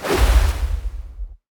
water sword Buff 3.wav